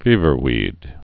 (fēvər-wēd)